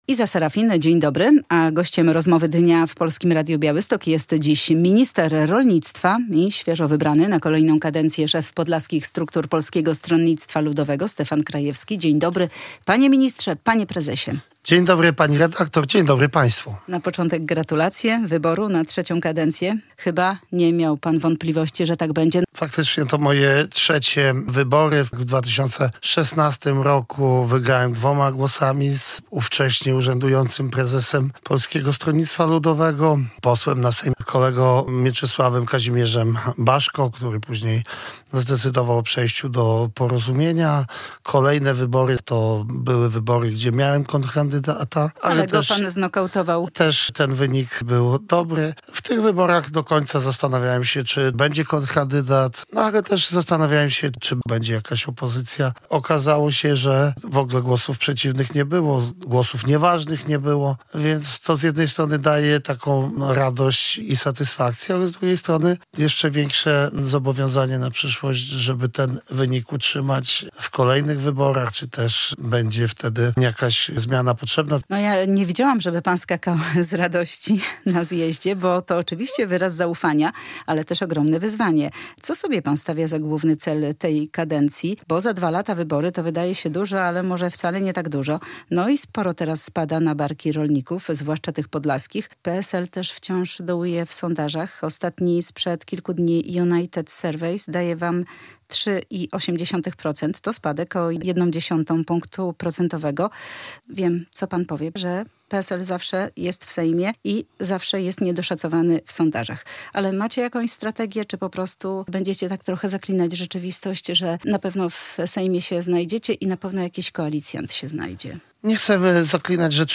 - Nie chcemy zaklinać rzeczywistości. Trzeba każdego dnia udowadniać, że pracuje się na rzecz swojego okręgu, swoich wyborców, ale też na rzecz Polski - mówił w Polskim Radiu Białystok Stefan Krajewski.
Radio Białystok | Gość | Stefan Krajewski - minister rolnictwa